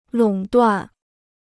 垄断 (lǒng duàn) พจนานุกรมจีนฟรี